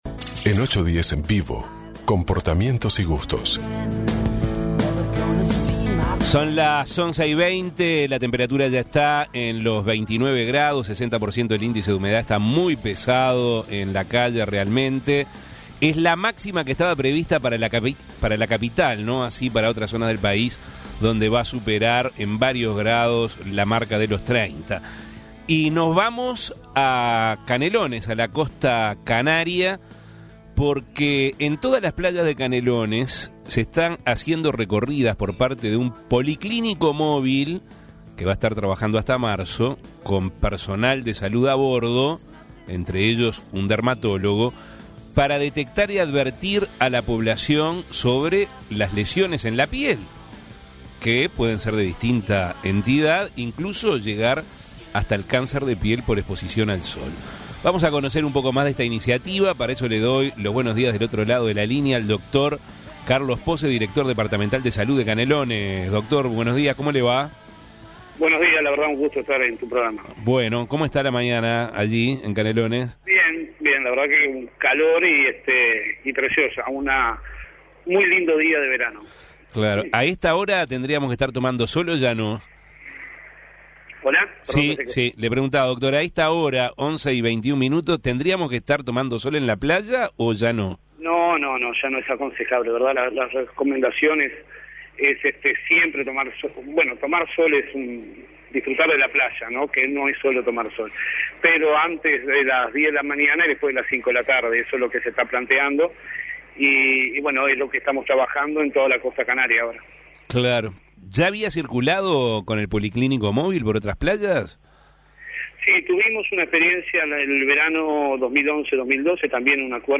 Por las altas temperaturas e intensidad del sol, todas las playas de Canelones están siendo recorridas por un policlínico móvil que trabajará hasta marzo con personal de salud a bordo, entre ellos un dermatólogo, para detectar y advertir a la población sobre este tipo de lesiones. Para conocer un poco más sobre esta iniciativa 810 Avances, Tendencias y Actualidad conversó con el doctor Carlos Pose, director departamental de Salud de Canelones.